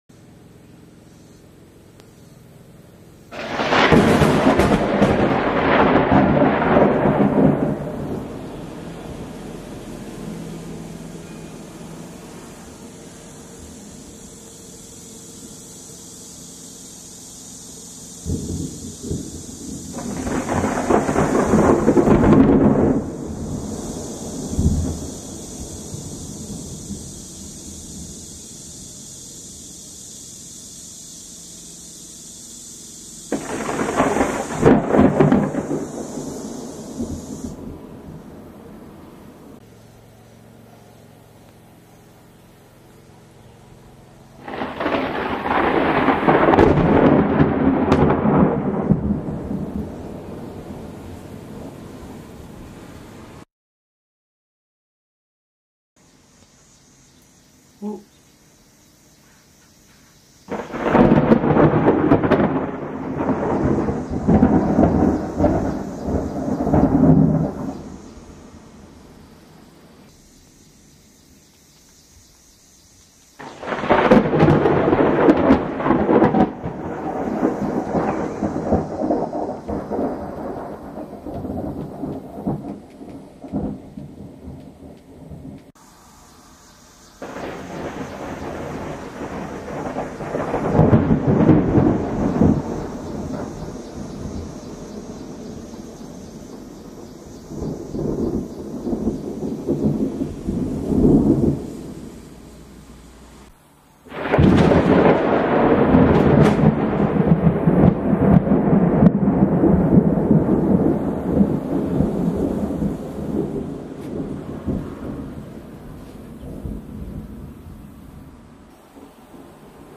دانلود صدای رعد و برق شدید و ترسناک در جنگل همراه با صدای جیرجیرک ها در طبیعت از ساعد نیوز با لینک مستقیم و کیفیت بالا
جلوه های صوتی